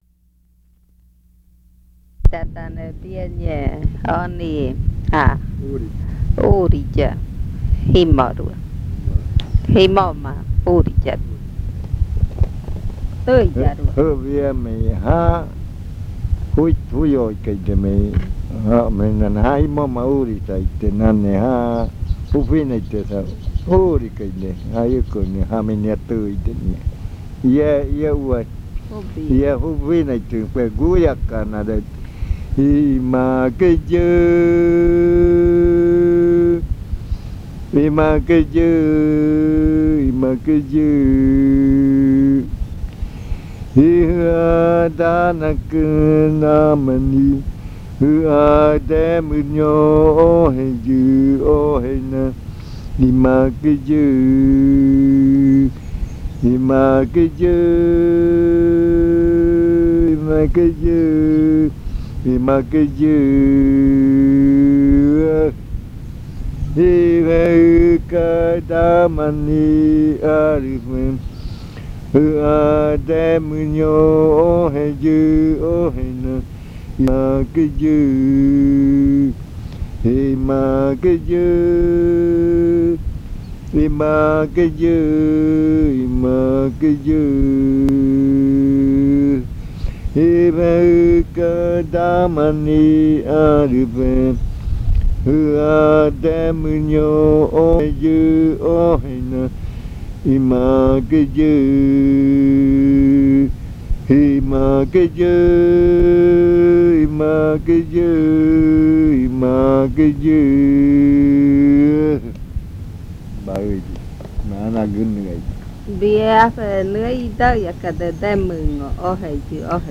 Leticia, Amazonas
Canción hablada (uuriya rua). Vengo gritando por el hambre.
Spoken chant (uuriya rua).